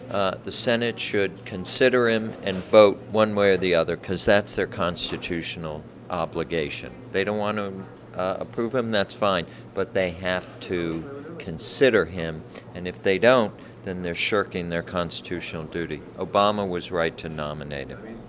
MAN IN A COFFEE SHOP WHO SUPPORTS PRESIDENT OBAMA
MOS-GARLAND-MAN-IN-COFFEE-SHOP-IN-PROVINCETOWN-MASS-WHO-SUPPORTS-PRESIDENT-OBAMA.mp3